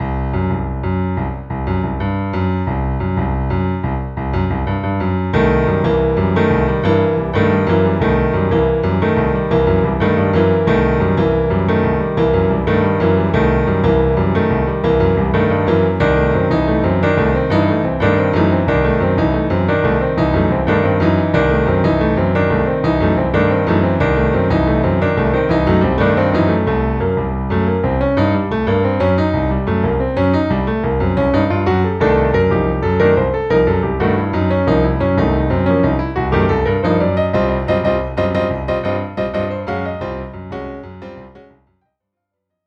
How I’m feeling about Trump’s wall, in the form of a short piano improv: